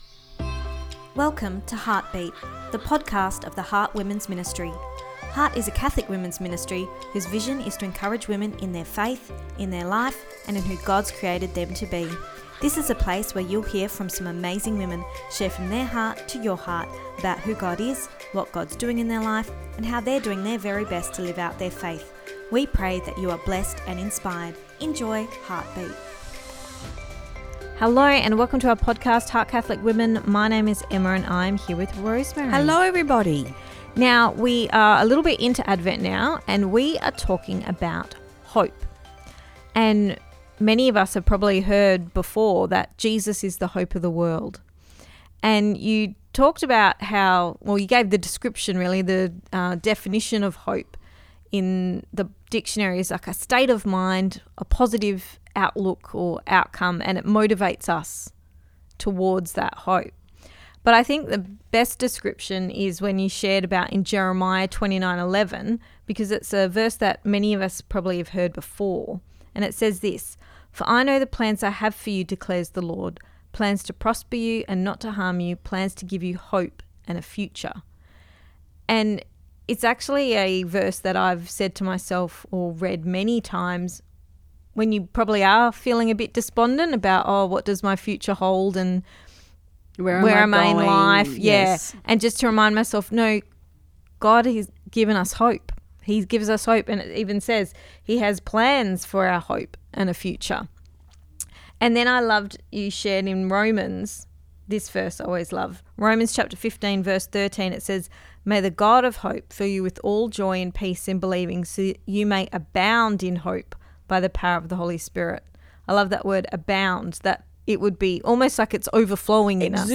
Ep276 Pt2 (Our Chat) – We Are Given Hope